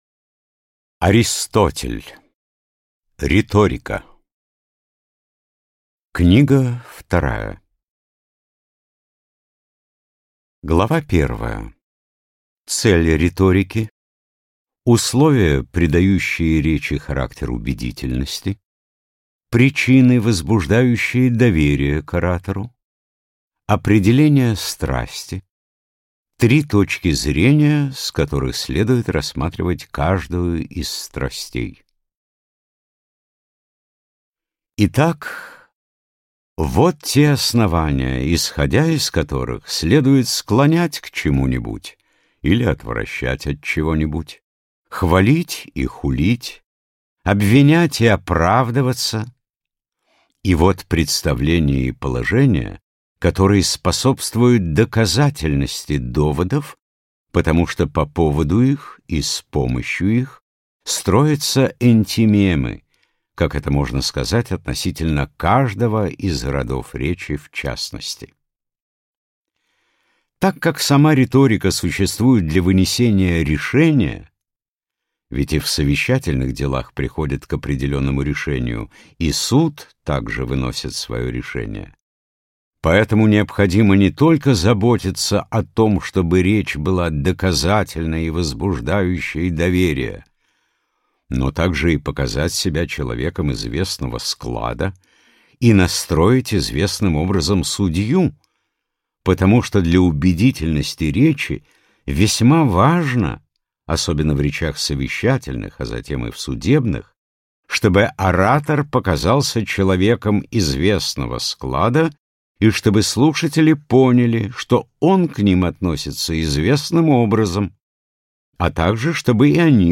Аудиокнига Риторика. Книга 2 | Библиотека аудиокниг